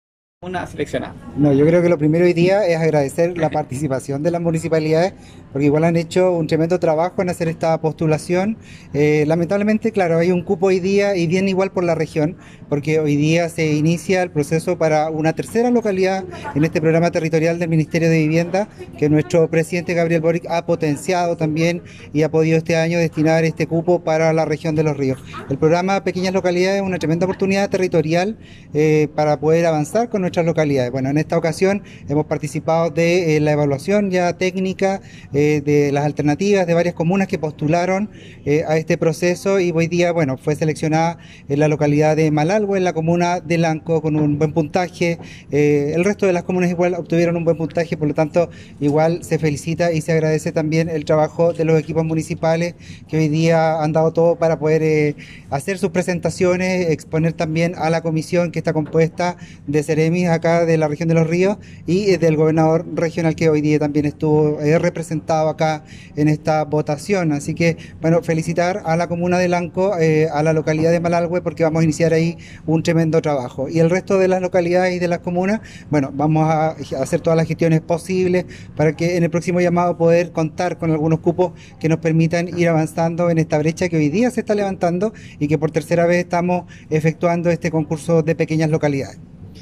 El Seremi de Vivienda, Daniel Barrientos Triviños, destacó la designación, “el programa Pequeñas Localidades es una tremenda oportunidad territorial para poder avanzar, y eso es algo que el Presidente Gabriel Boric ha señalado como una prioridad de su Gobierno.
Seremi-Daniel-Barrientos_-Pequenas-Localidades.mp3